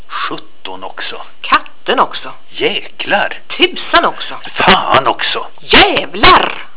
Для прослушивания или скачивания звукового файла, содержащего произношение приведенных примеров, пожалуйста, нажмите на название соответствующего раздела.